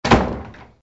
Door_Close_1.ogg